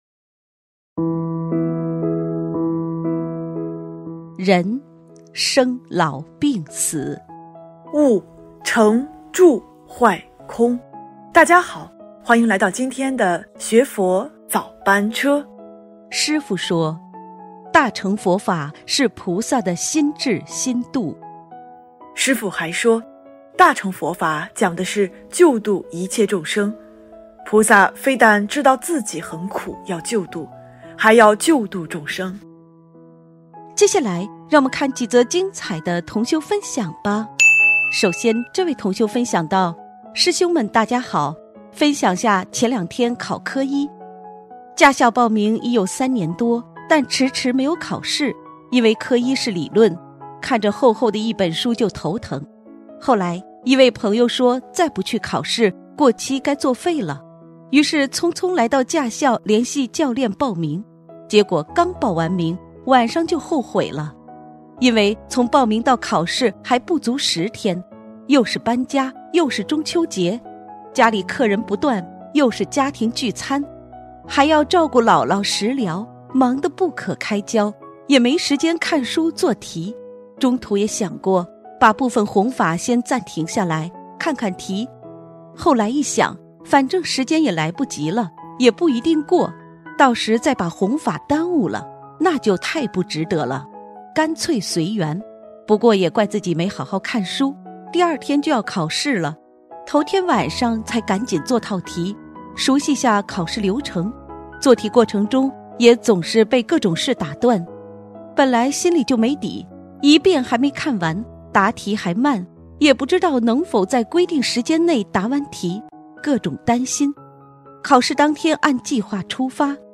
——2019年11月9日新西兰•奥克兰弟子开示